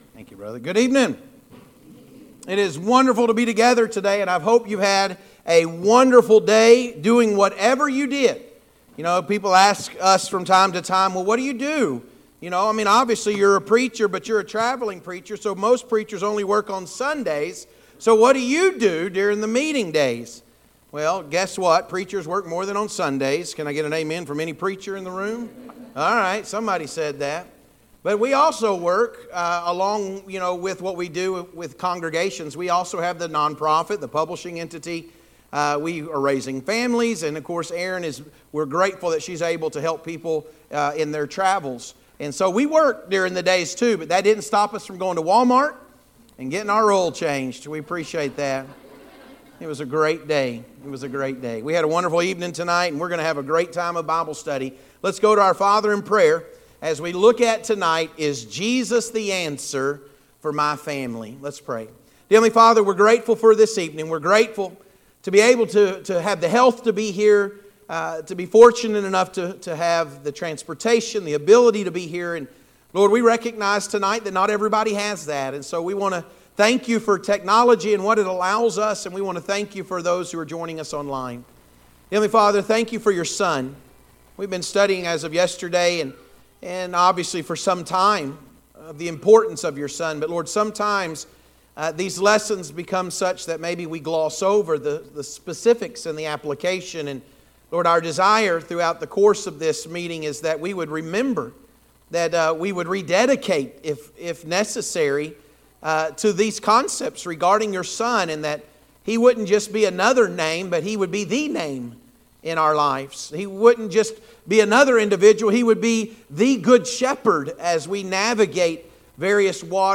Is Jesus the Answer? Gospel Meeting